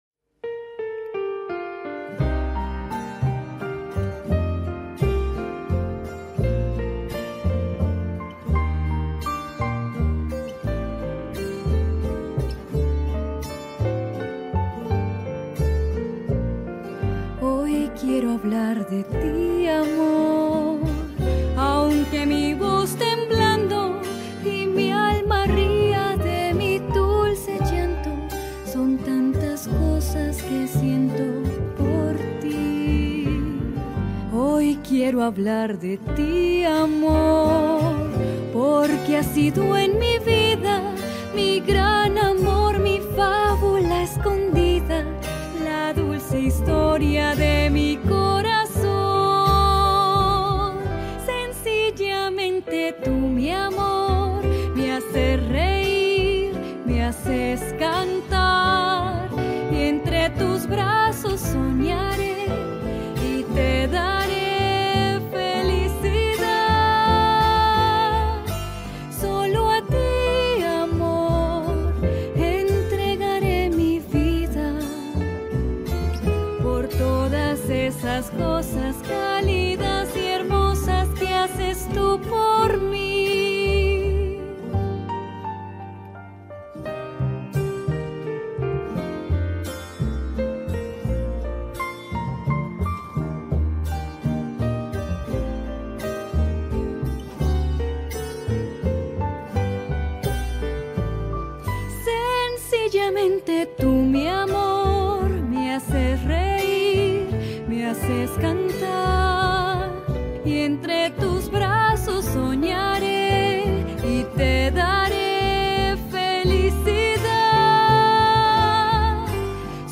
Pasillo